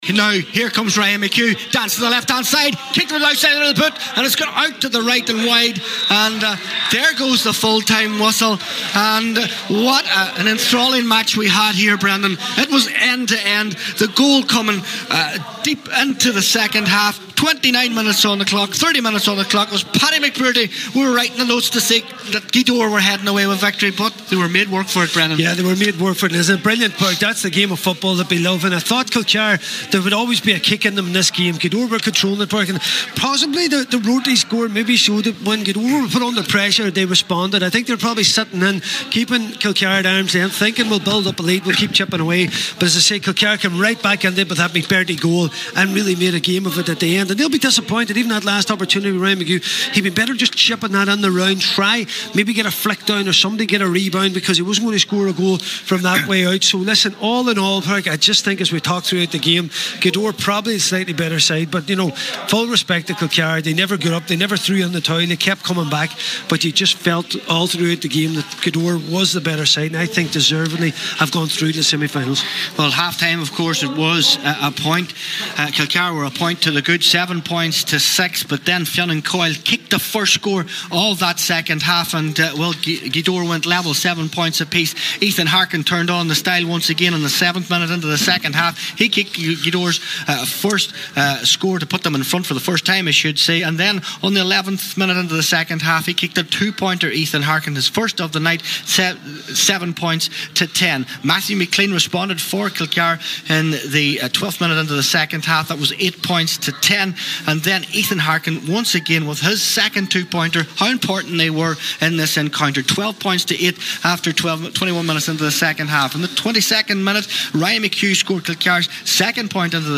have the full time report…